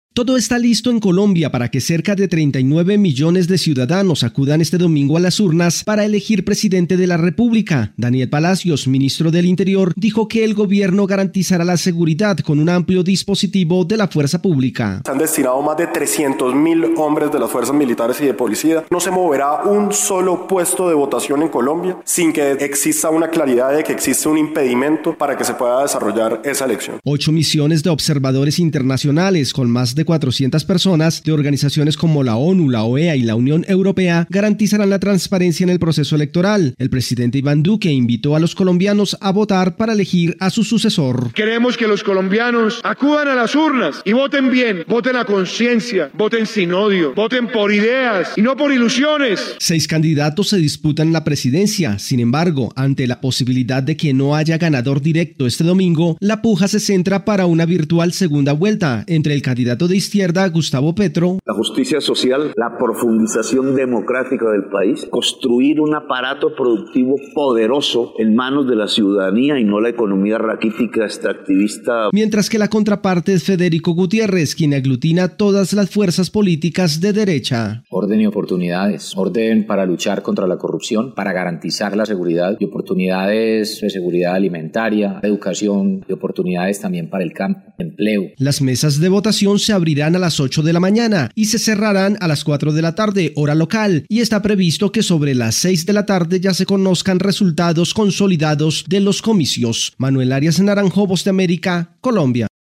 Colombia se prepara para las elecciones presidenciales del domingo con un fuerte dispositivo de seguridad e implementación de medidas restrictivas. Desde Colombia informa el corresponsal de la Voz de América